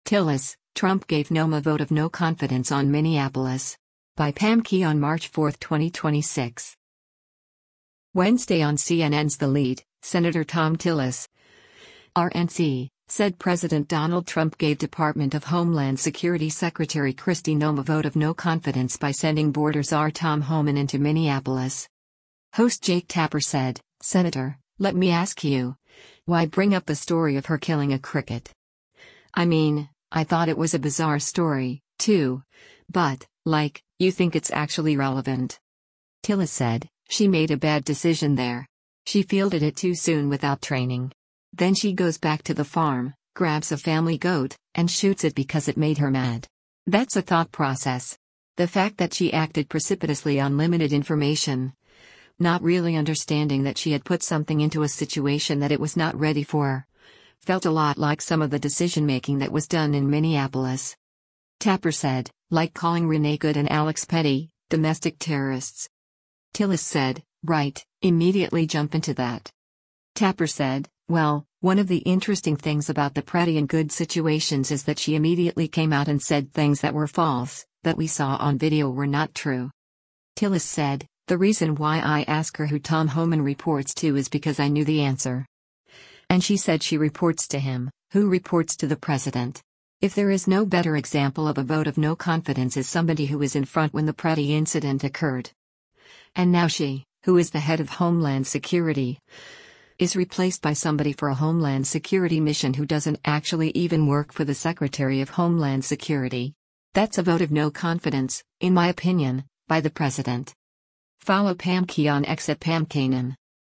Wednesday on CNN’s “The Lead,” Sen. Thom Tillis (R-NC) said President Donald Trump gave Department of Homeland Security Secretary Kristi Noem a “vote of no confidence” by sending border czar Tom Homan into Minneapolis.